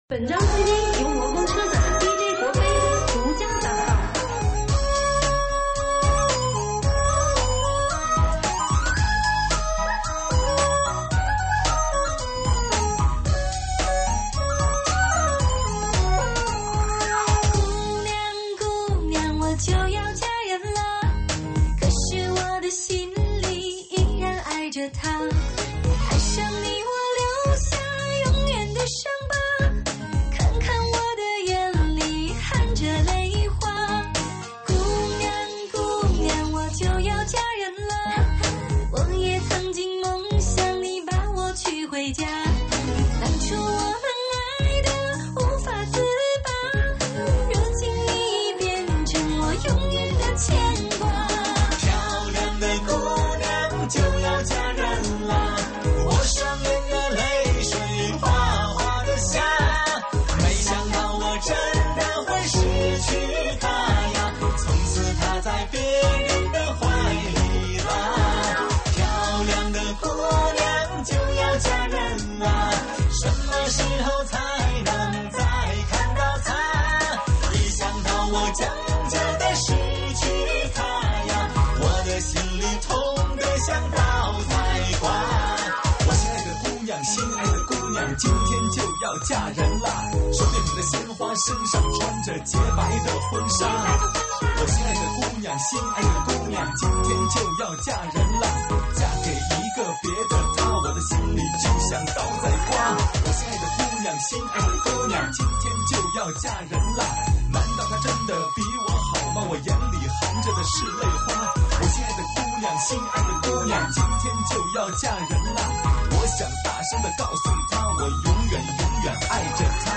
(3D全景环绕)